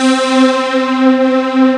EXCITA PAD 1 1.wav